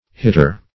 Hitter \Hit"ter\, n.